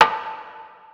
G.O.O.D. Snare.aif